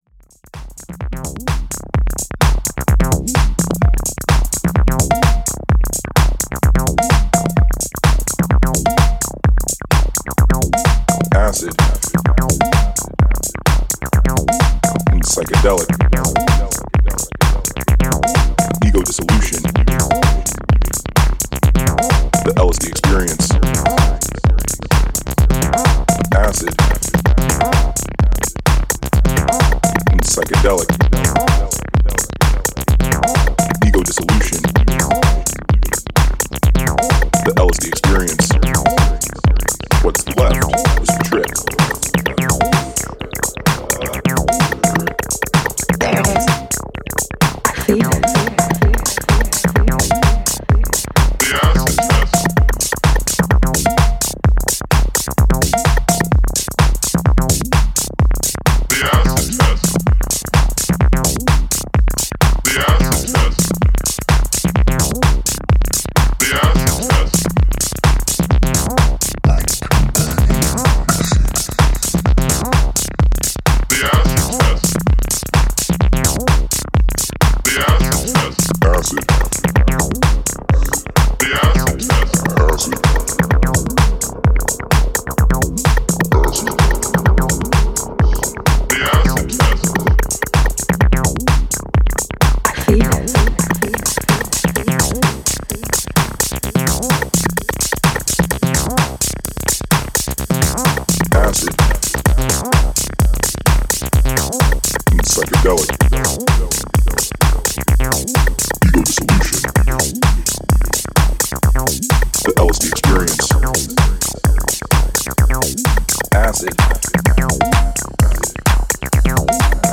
初期シカゴの混沌を呼び覚ます汁気たっぷりのアシッド使いに痺れる